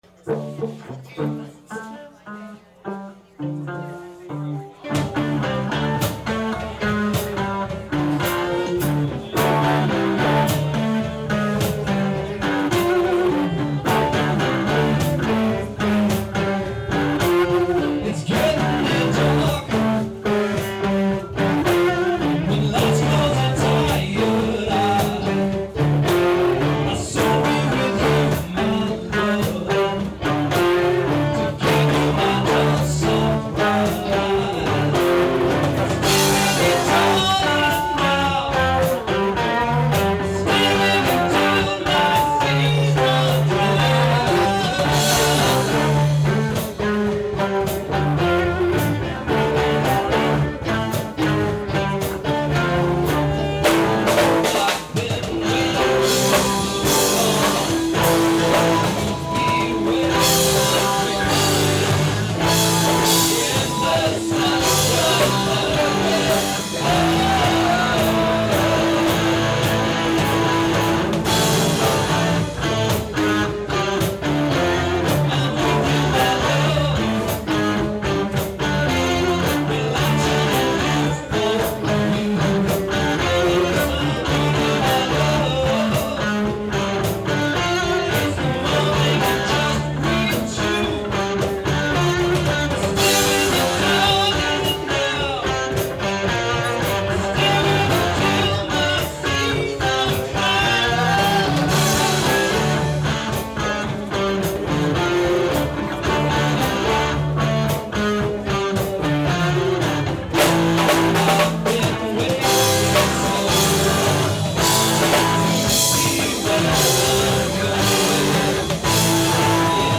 Please understand that not only were these outdoor audience recordings made under extremely difficult conditions, but also that much of the band had not even met before the party, let alone practiced together, and the jam lasted well under an hour.
Nice view, but rough audio...